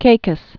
(kākəs, -kōs)